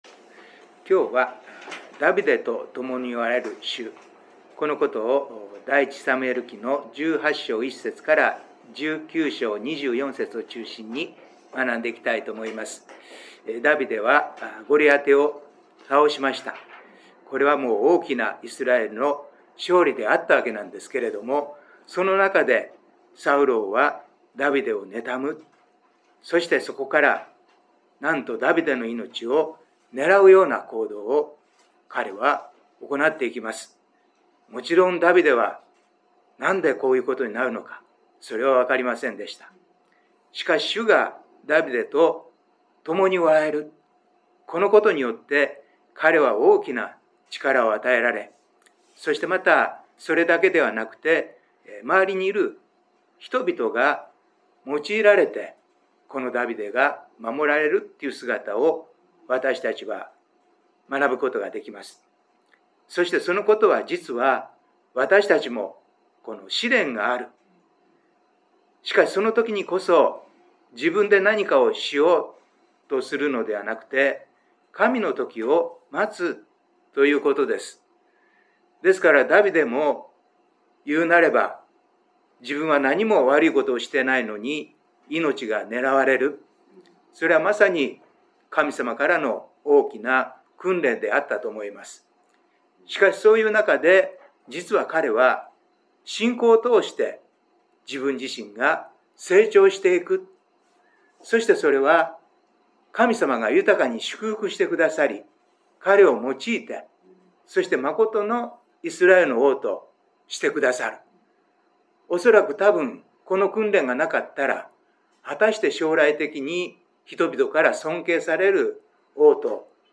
2026/3/4 聖書研究祈祷会